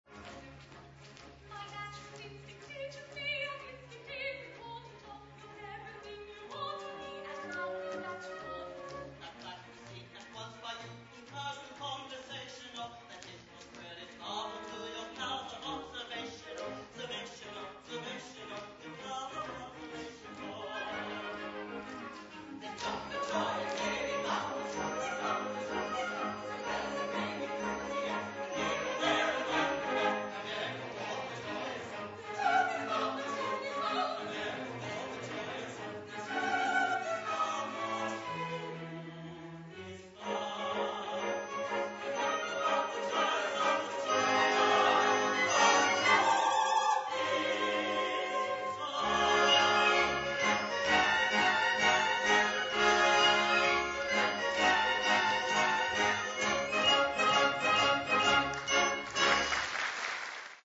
Jeeves Audio Services is pleased to be associated with the Gilbert and Sullivan Society of Victoria, making live recordings of the society's productions.